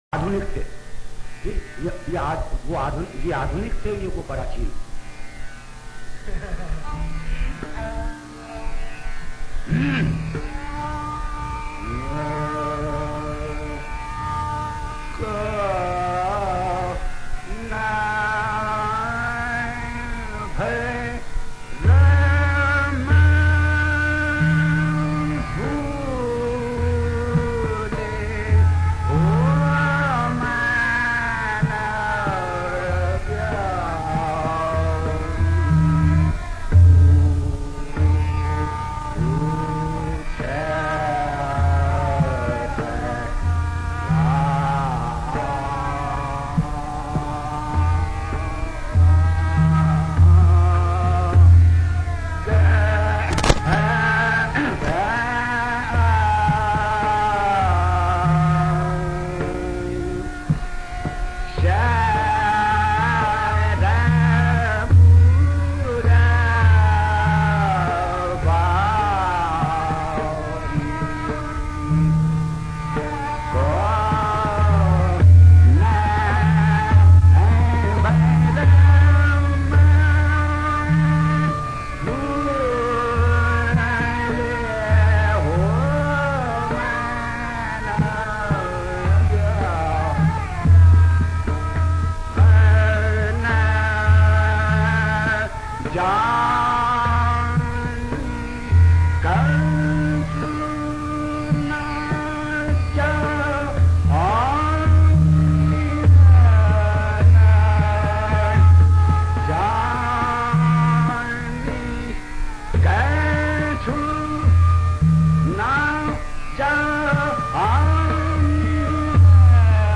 Todi (Alaap) Todi (Dhrupad)